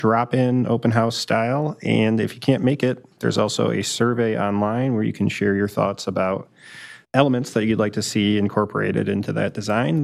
Here’s councilmember Chris Burns.